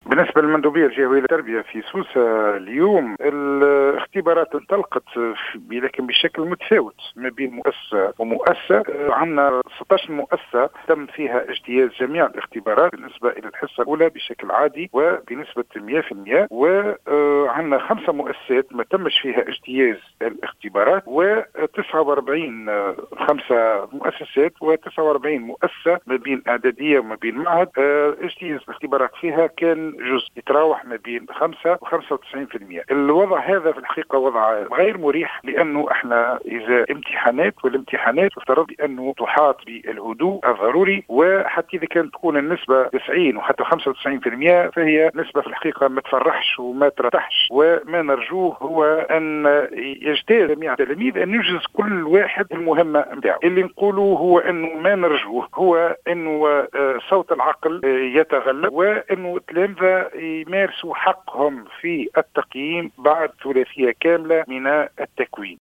قال المندوب الجهوي للتربية بسوسة نجيب الزبيدي في تصريح للجوهرة "اف ام" أن الاختبارات انطلقت اليوم ولكن بشكل متفاوت بين مؤسسة وأخرى.